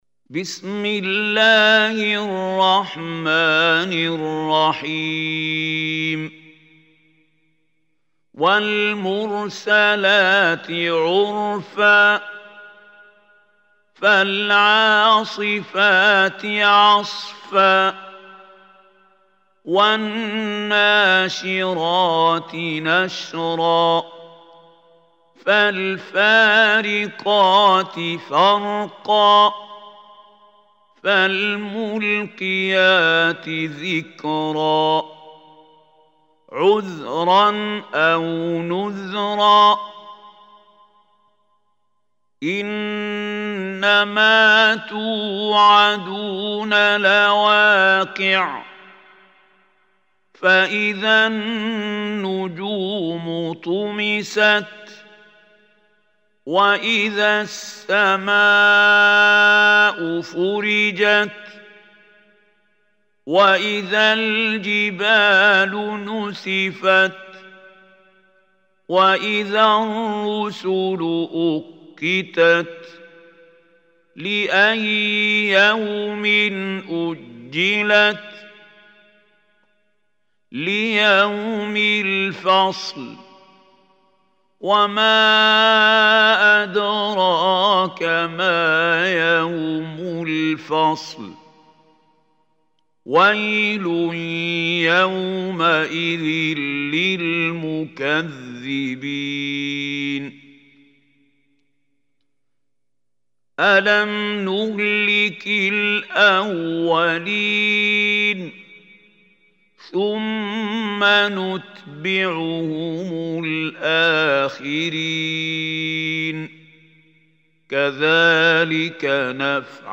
Surah Mursalat Recitation by Mahmoud Hussary
Surah Mursalat is 77 chapter of Holy Quran. Listen or play online mp3 tilawat / recitation in arabic in the beautiful voice of Sheikh Mahmoud Khalil Al Hussary.